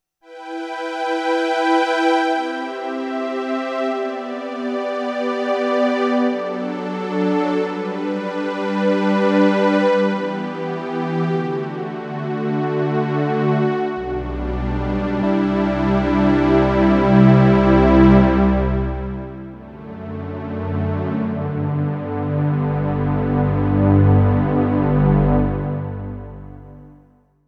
Lastly here's a simple string patch using only 1 oscillator with the Supersaw waveshape. There is no modulation of pitch or shape.
Solaris Supersaw Simple String pad
(I used a basic delay module in Scope for examples #3, #4 & #5. They are both using the lowpass 24dB filter as well.)
SolSupersawSimpleString.wav